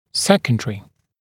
[‘sekəndərɪ][‘сэкэндэри]второстепенный, побочный, вторичный, производный, вспомогательный, подсобный